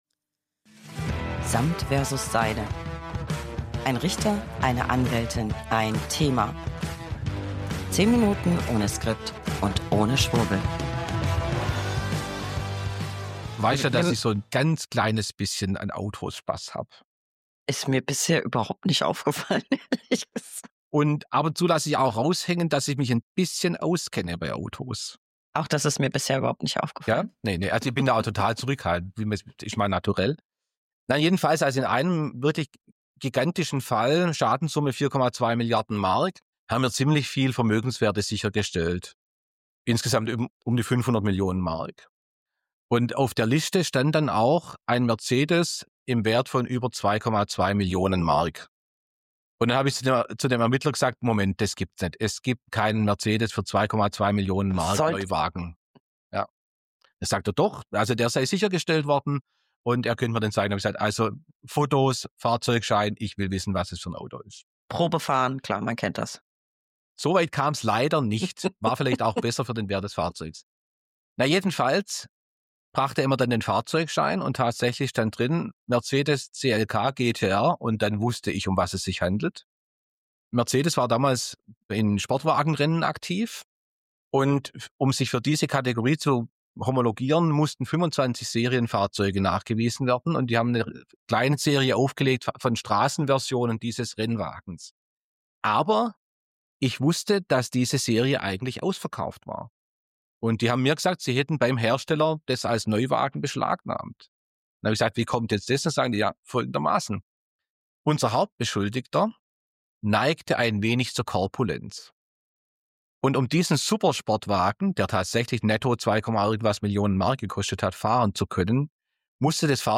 1 Anwältin + 1 Richter + 1 Thema.
10 Minuten ohne Skript und ohne Schwurbel.